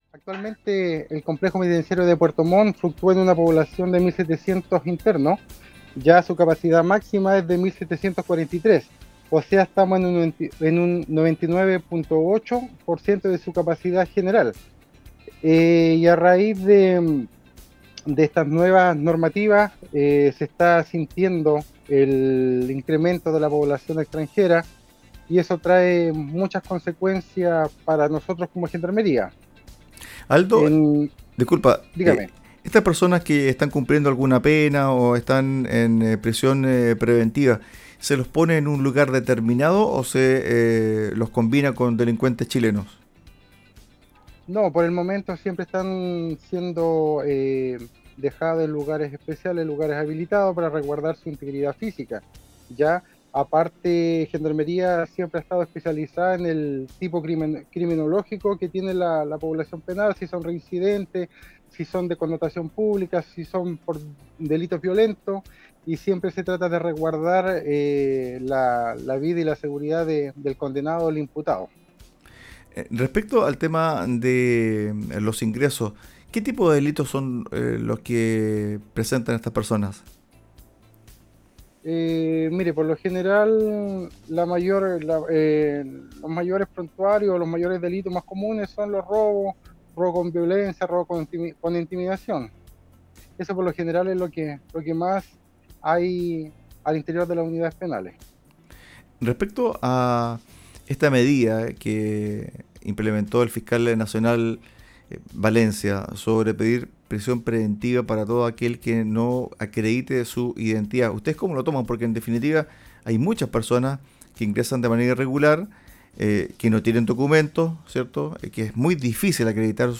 En conversación con Radio Sago